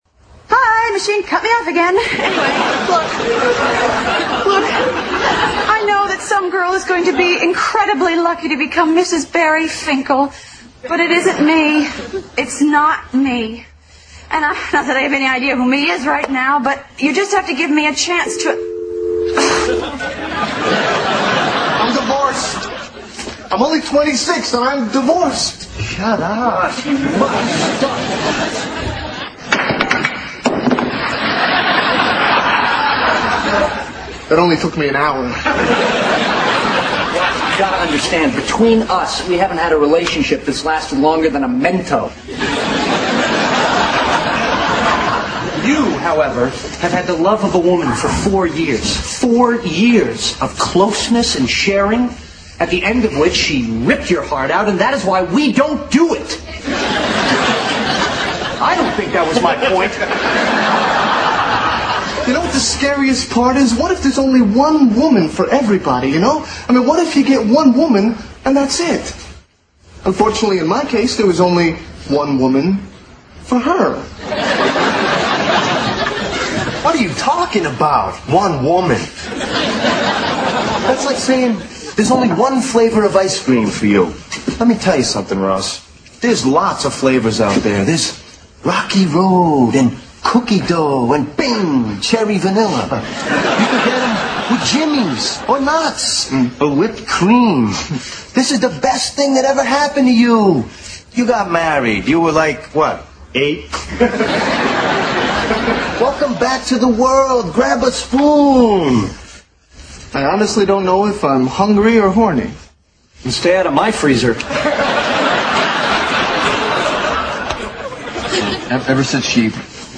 在线英语听力室老友记精校版第1季 第5期:莫妮卡的新室友(5)的听力文件下载, 《老友记精校版》是美国乃至全世界最受欢迎的情景喜剧，一共拍摄了10季，以其幽默的对白和与现实生活的贴近吸引了无数的观众，精校版栏目搭配高音质音频与同步双语字幕，是练习提升英语听力水平，积累英语知识的好帮手。